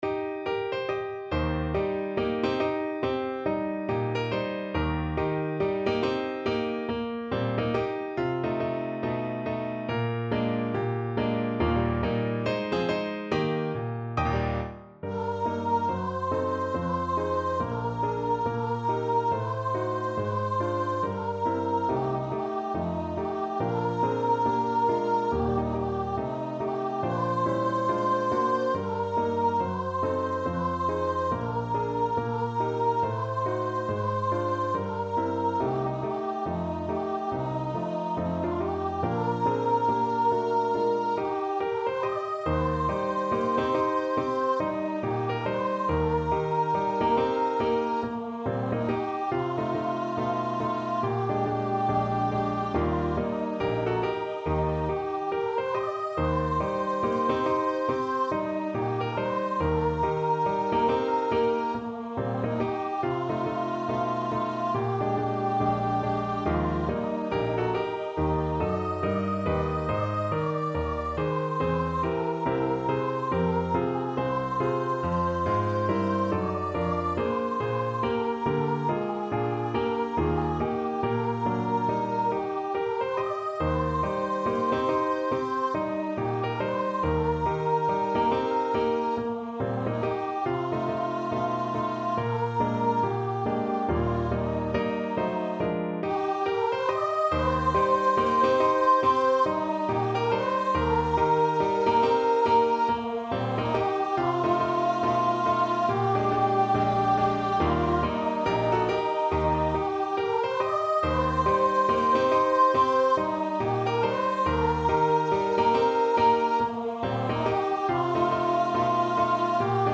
2/2 (View more 2/2 Music)
= 70 Moderato
Pop (View more Pop Voice Music)